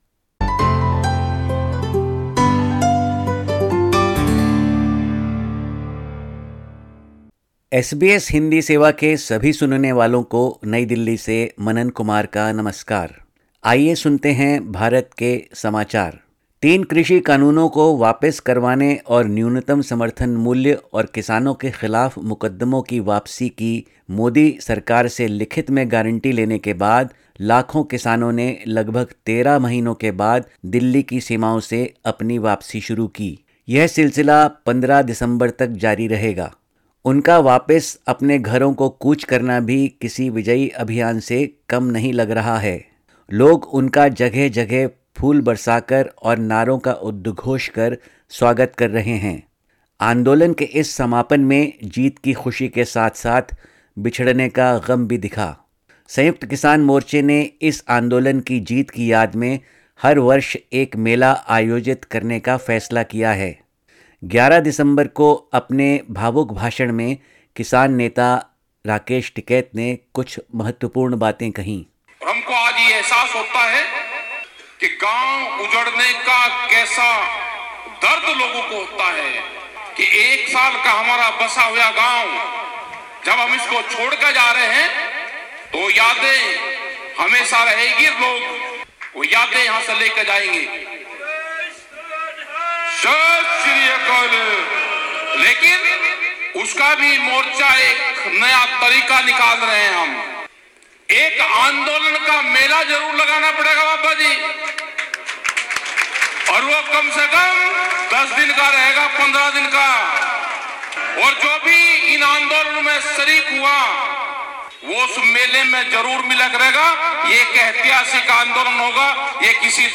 भारत के समाचार हिन्दी में